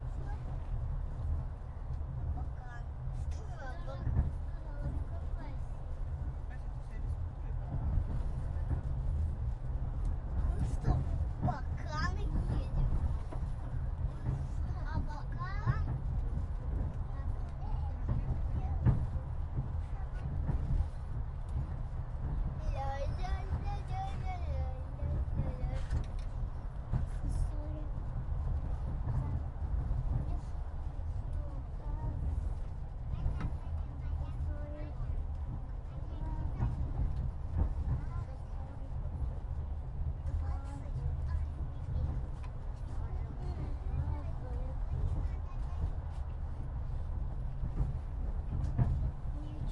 描述：西伯利亚火车乘客的讲话。
标签： 铁路 儿童 言语 火车 乘客1 现场录音 对话 女人
声道立体声